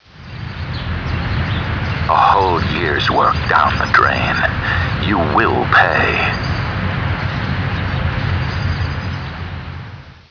THE LONDON SUN & NEWS OF THE WORLD's confidential sources have discovered that a series of threatening and possibly revealing telephone calls are being received from a source or sources unknown who apparently have information on the whereabouts of Meg Townsend.
Written transcripts of audio clues (TXT files) are available for users without sound-cards -- but where possible, we recommend downloading the sound files, because the background sounds and audio subtleties can be helpful in formulating your theory!